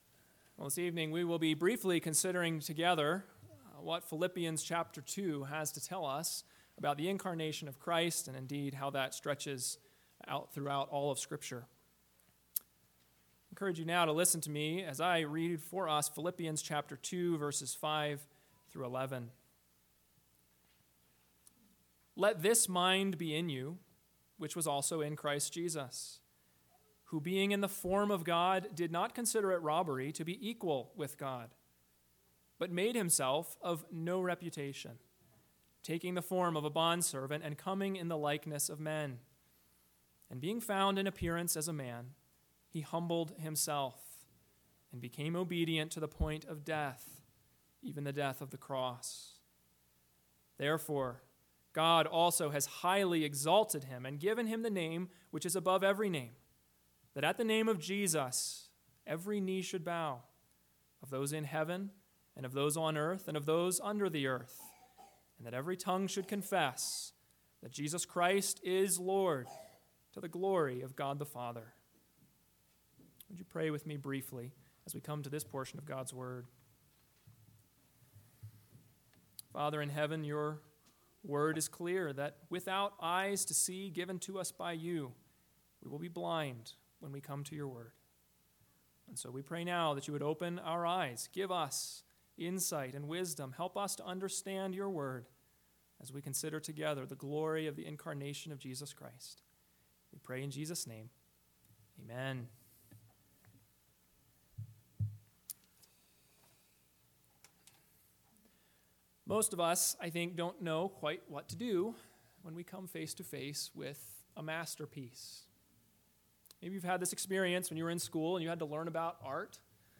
Christmas Eve Message